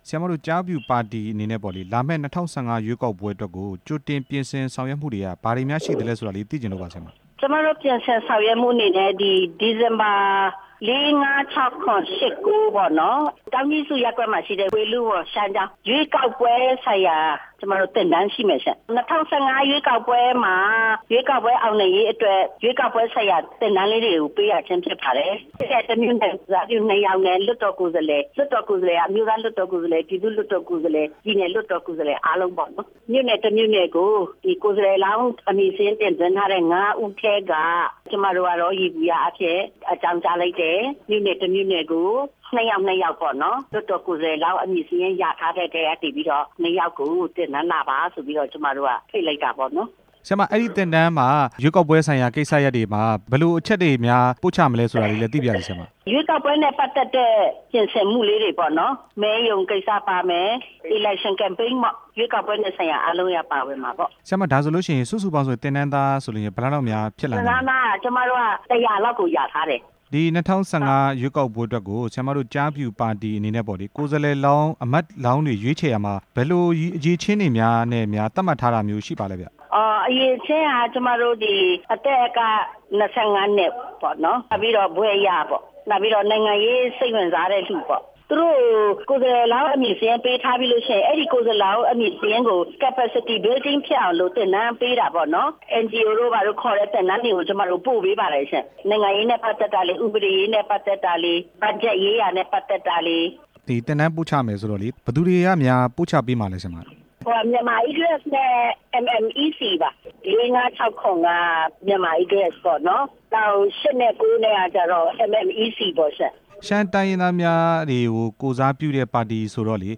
မေးမြန်းချက် အပြည့်အစုံ